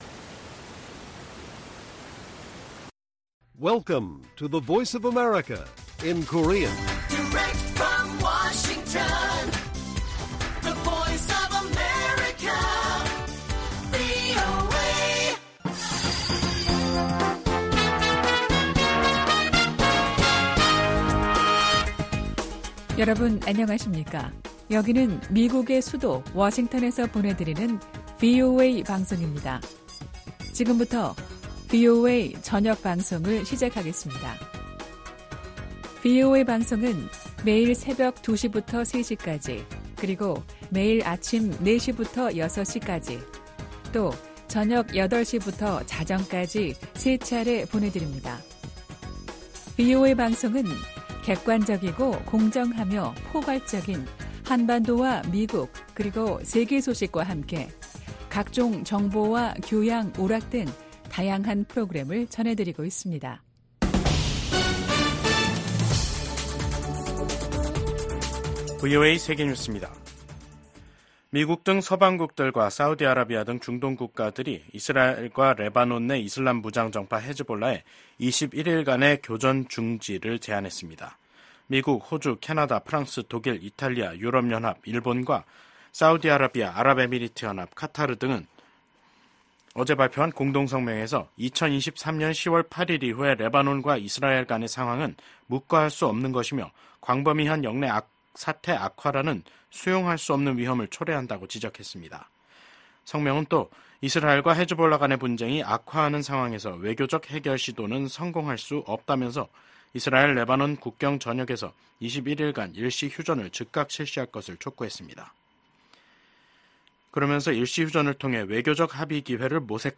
VOA 한국어 간판 뉴스 프로그램 '뉴스 투데이', 2024년 9월 26일 1부 방송입니다. 백악관은 러시아가 북한에 제공할 포탄 지원의 대가가 한반도 상황을 더욱 불안정하게 만들 수 있다는 우려를 나타냈습니다. 유엔총회가 열리고 있는 미국 뉴욕에서 북한 인권 문제, 특히 납북자를 주제로 한 행사가 열렸습니다. 한국 국가정보원은 북한이 오는 11월 미국 대선 이후 7차 핵실험을 감행할 가능성이 있다고 전망했습니다.